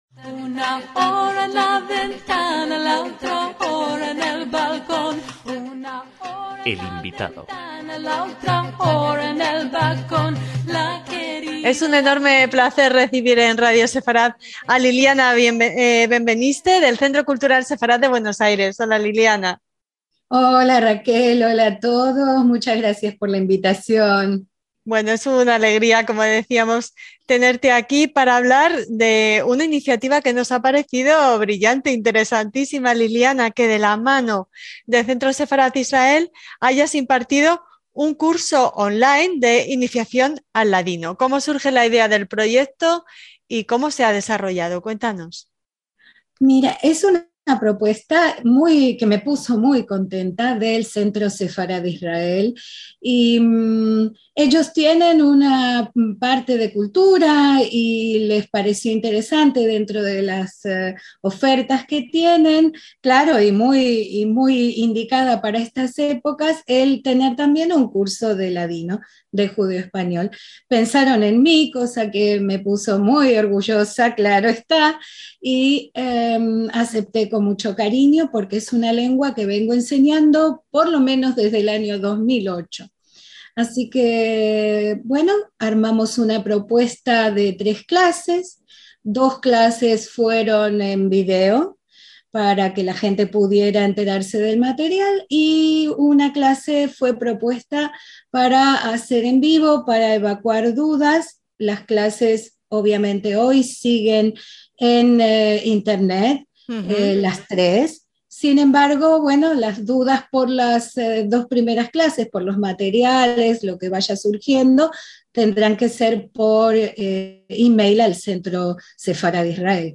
Curso online de iniciación al ladino
EL INVITADO DEL CENTRO SEFARAD-ISRAEL